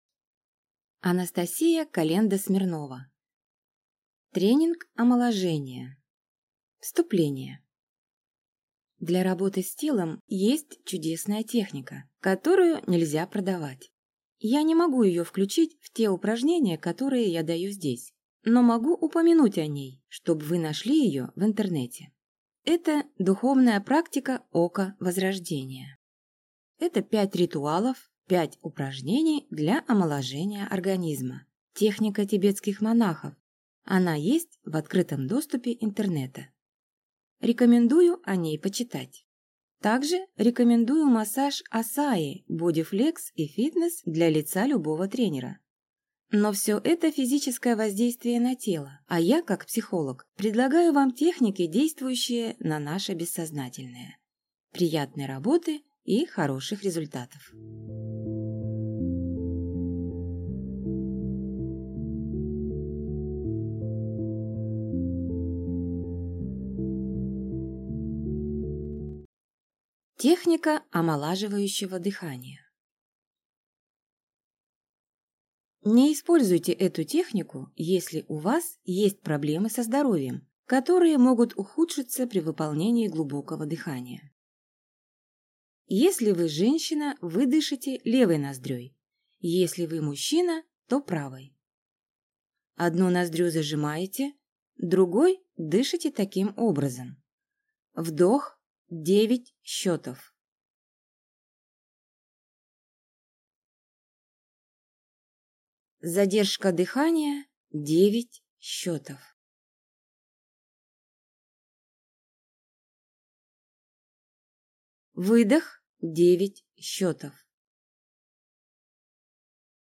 Аудиокнига Тренинг Омоложение | Библиотека аудиокниг